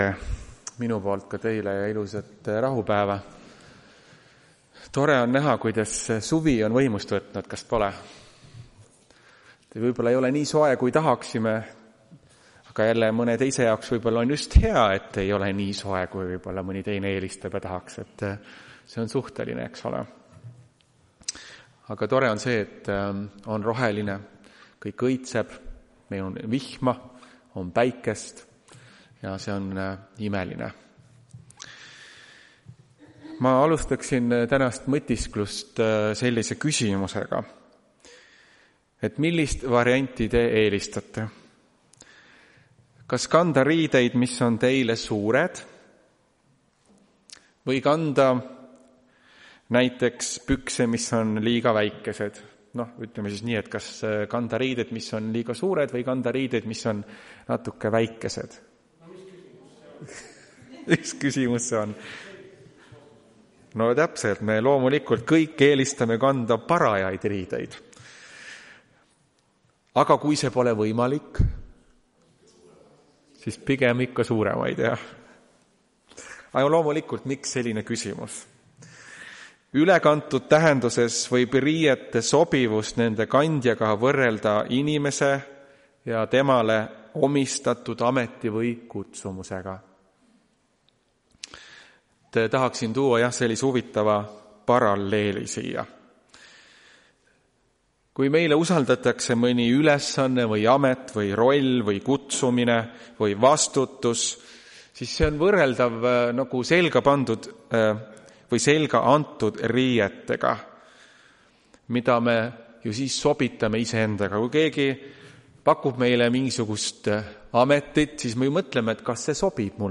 Tartu adventkoguduse 07.06.2025 hommikuse teenistuse jutluse helisalvestis.
Jutlused